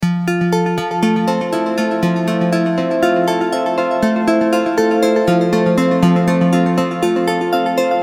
Guitar Synth Arpeggiator Loop
Description: Guitar synth arpeggiator loop. A great ambient dreamy theme, perfect as background music for games, videos, or applications.
Genres: Synth Loops
Tempo: 120 bpm
Guitar-synth-arpeggiator-loop.mp3